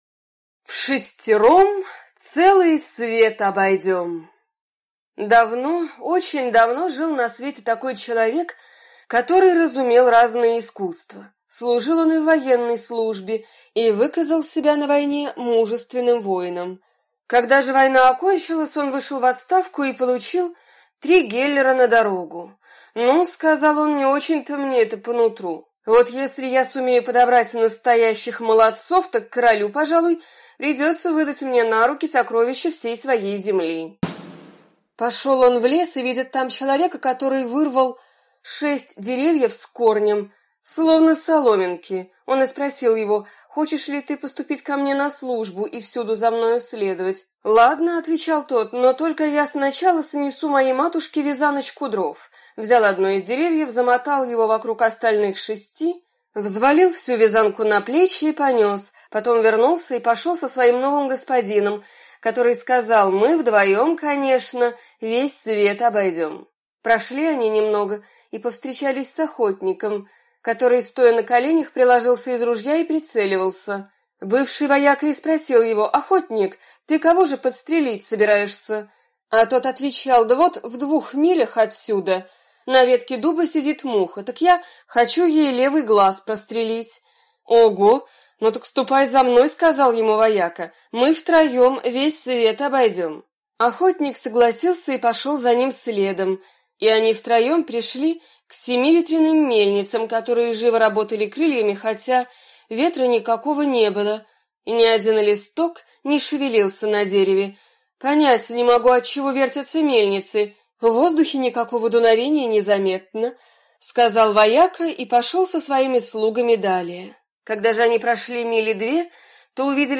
Вшестером весь свет обойдем - аудиосказка Братьев Гримм. Слушать сказку про отставного солдата онлайн на сайте.